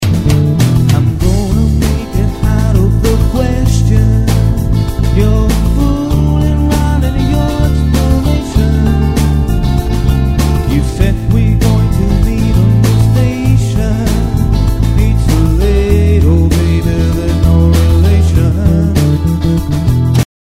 The 60s and 70s R & B re-visited